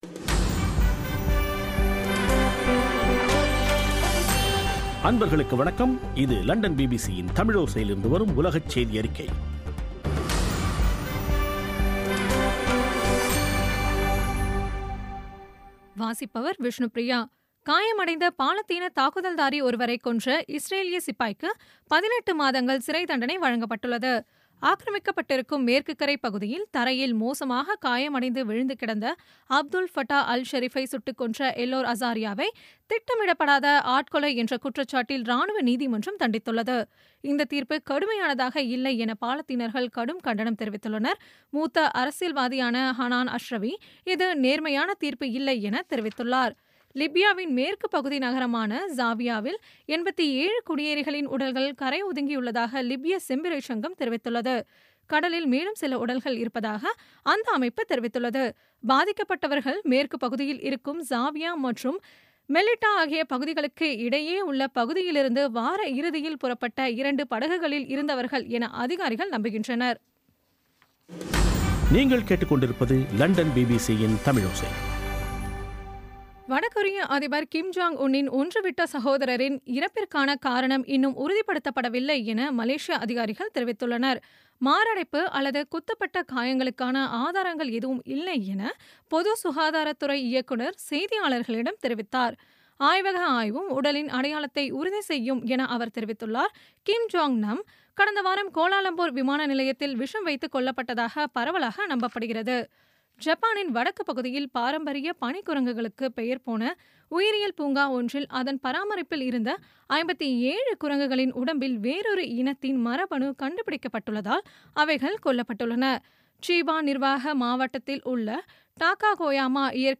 பிபிசி தமிழோசை செய்தியறிக்கை (21/02/2017)